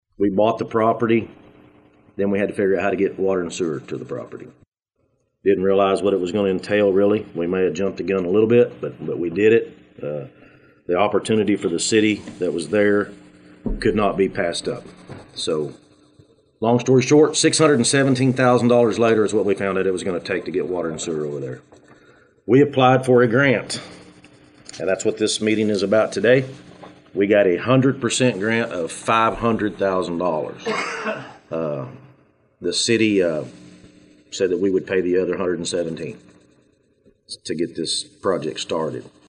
Today the City of Thayer held a press conference to talk about their plans for the project.